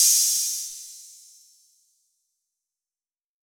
6SPLASH.wav